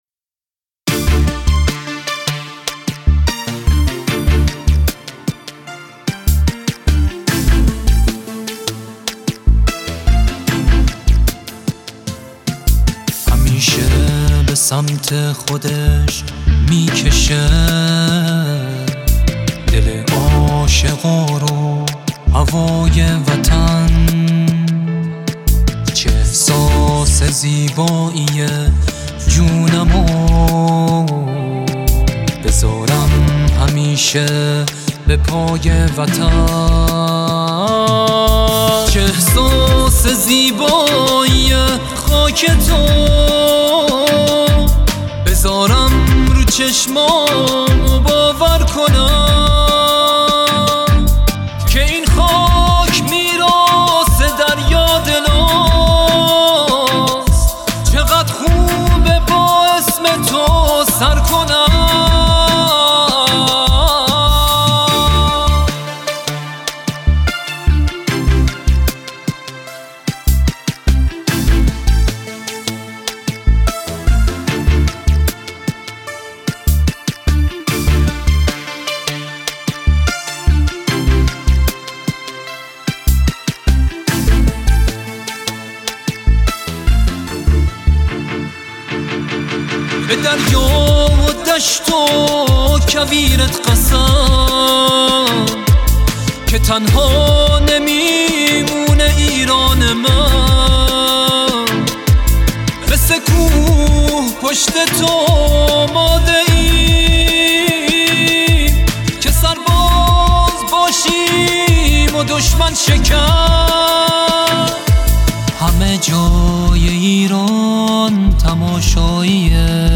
موزیک ویدئو حماسی انقلابی